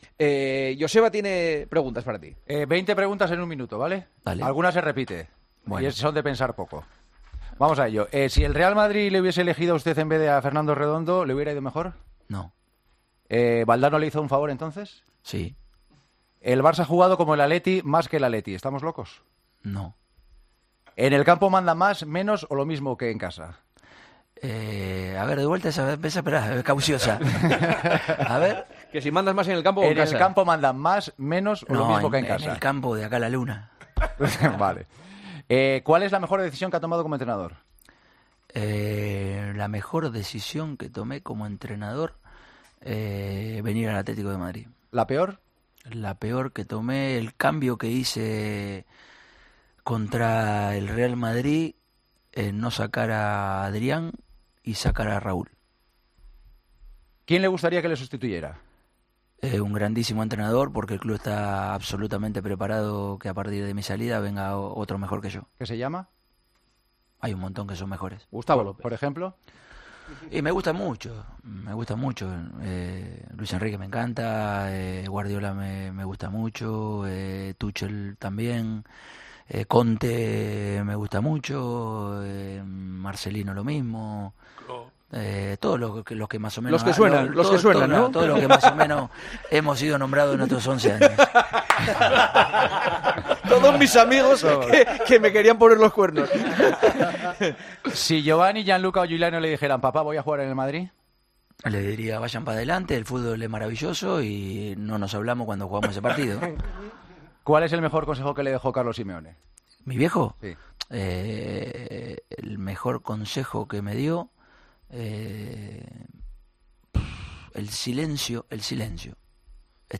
Escucha el comentario de Juanma Castaño en Herrera en COPE de este viernes 2 de junio de 2023